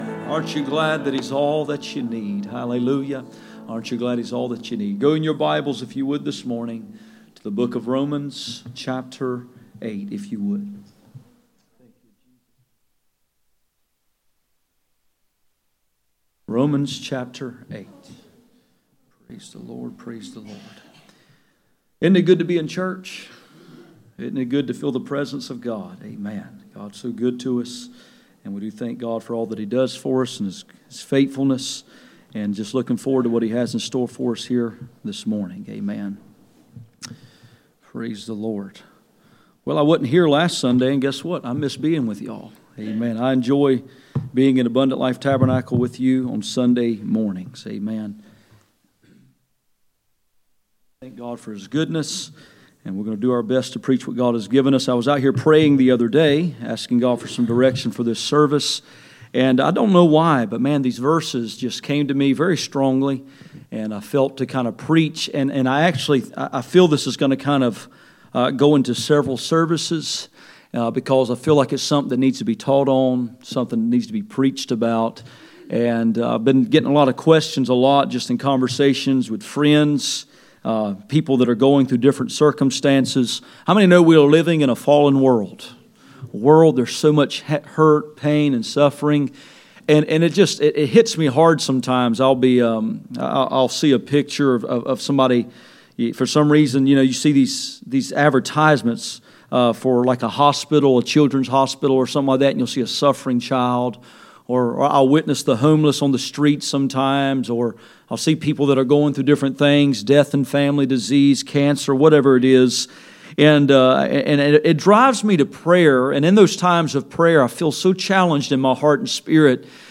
Romans 8:18-27 Service Type: Sunday Morning %todo_render% « We will not sit down until he comes Groom for Glory